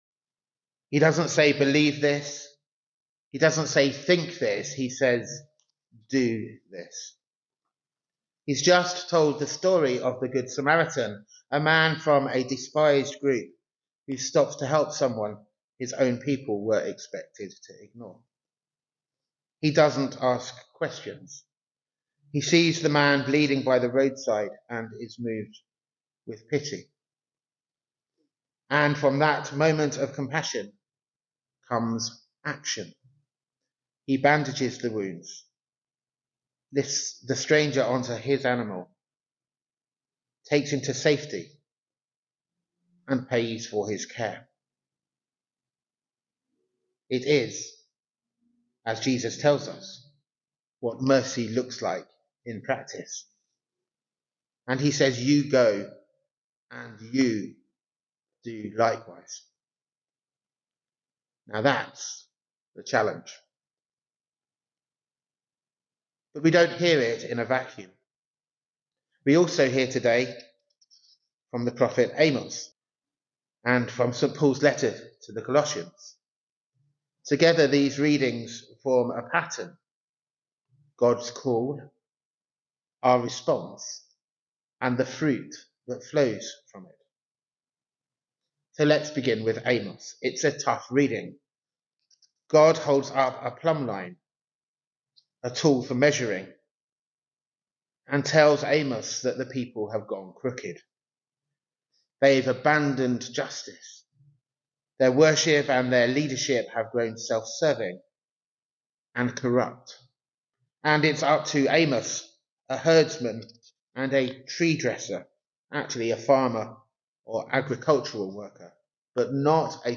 Recent Sermons and Other Audio Recordings -